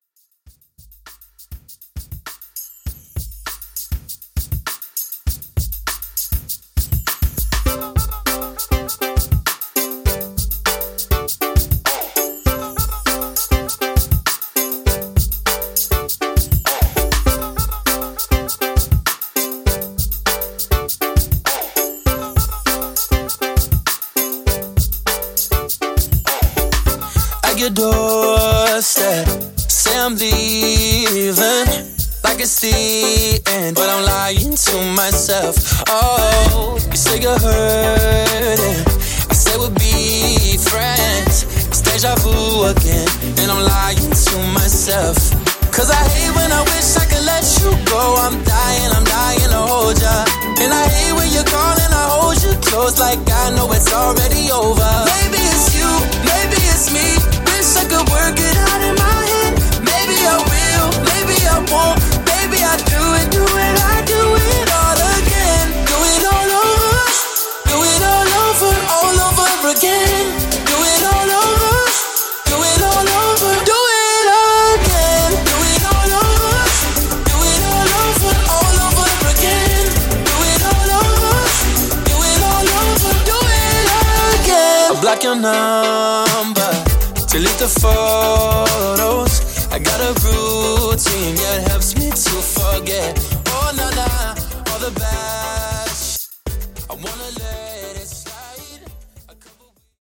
Top40 Redrum)Date Added